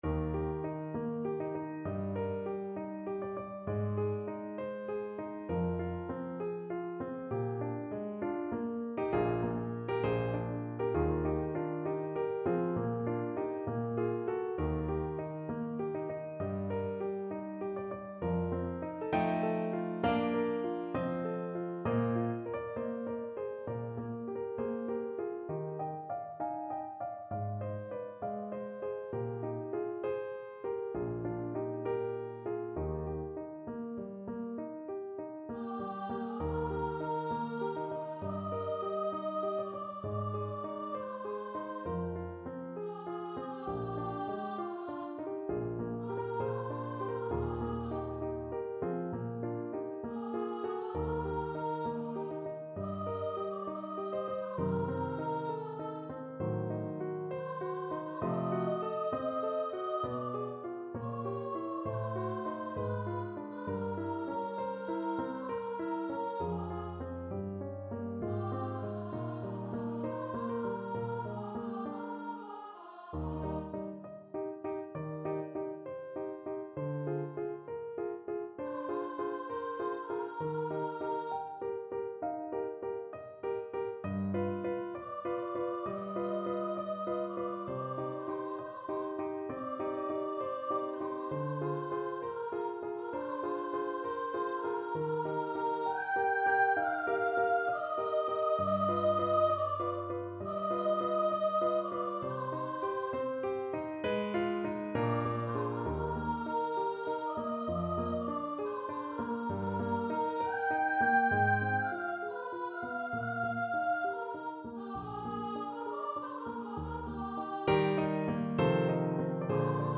4/4 (View more 4/4 Music)
Andantino =66 (View more music marked Andantino)
Classical (View more Classical Soprano Voice Music)